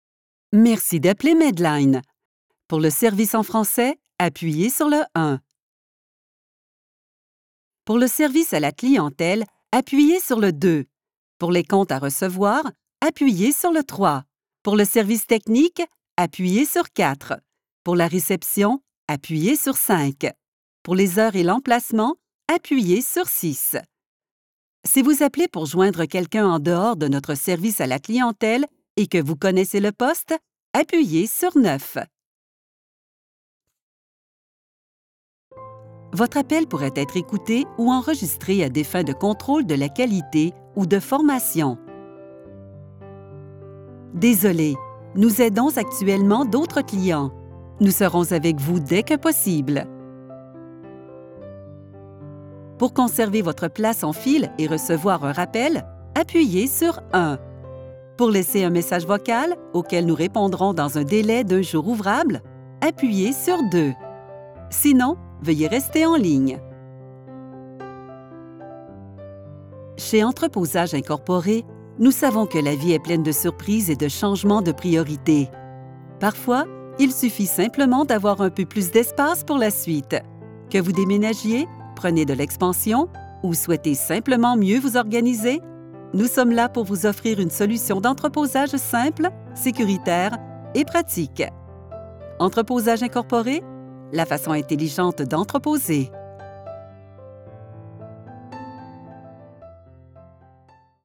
Accessible, Reliable, Corporate
Telephony